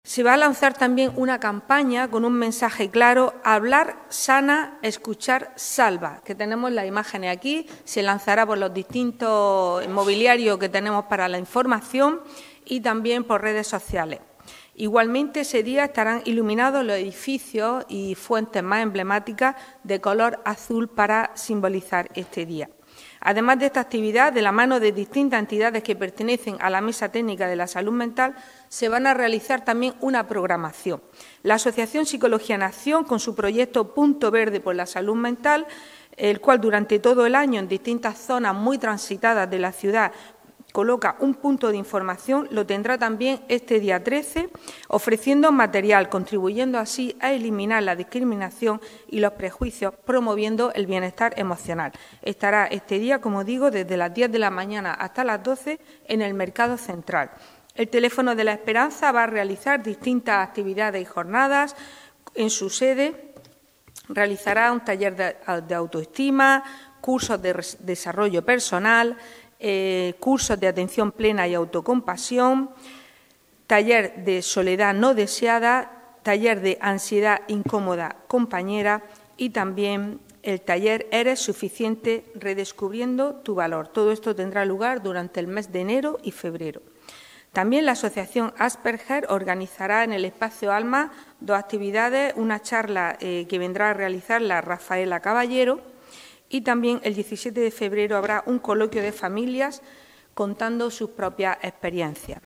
Entidades que han arropado a la concejala en la rueda de prensa de presentación.